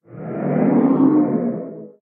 守卫者：低鸣
守卫者在水中空闲
Minecraft_guardian_ambient1.mp3